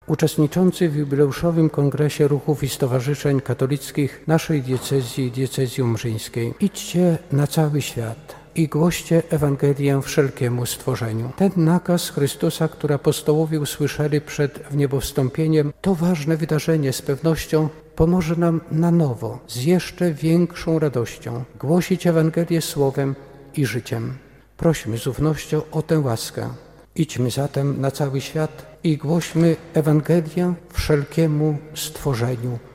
Uroczystą mszą świętą w Katedrze Łomżyńskiej rozpoczął się w sobotę (25.04) Jubileuszowy Kongres Ruchów i Stowarzyszeń Katolickich Diecezji Łomżyńskiej.
Liturgii przewodniczył biskup Tadeusz Bronakowski, podkreślając znaczenie wspólnot w życiu Kościoła oraz ich rolę w budowaniu więzi społecznych: